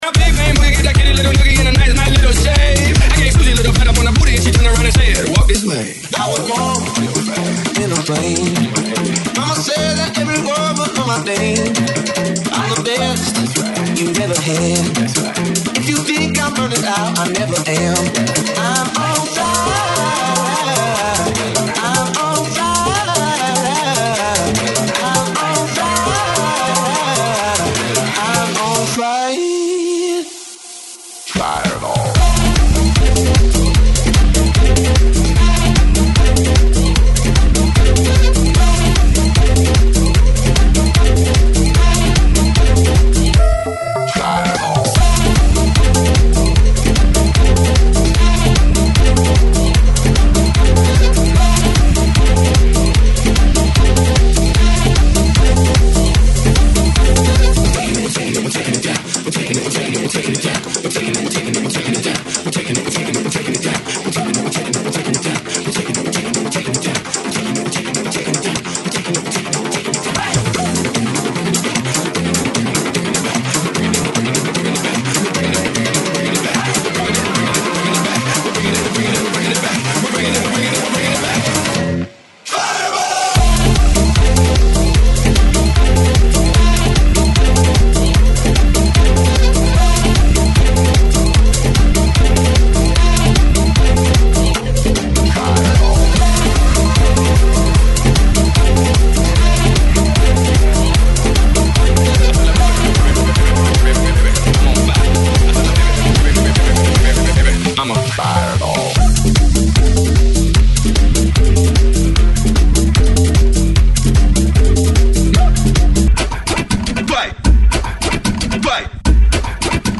GENERO: LATINO REMIX
AEROBICS (STEP-HILOW)